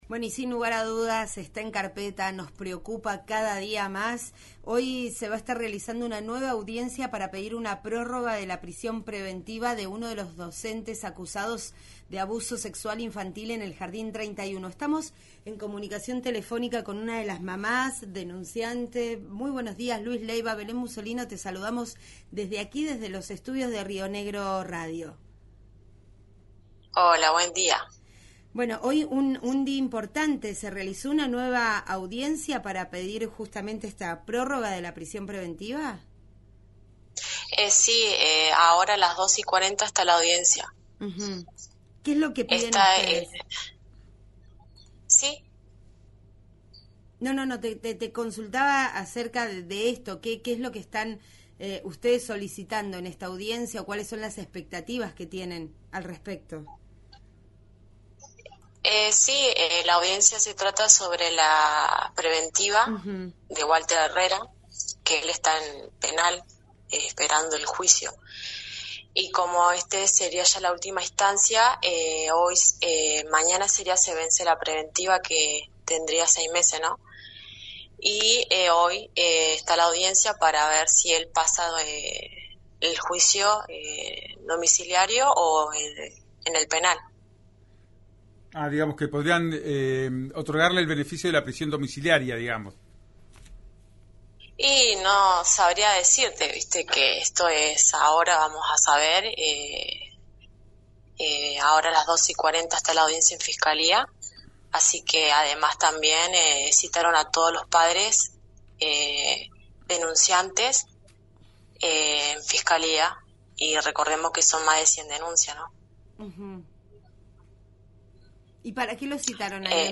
Escuchá a una de las madres del Jardín 31, en «Ya es tiempo» RÍO NEGRO RADIO: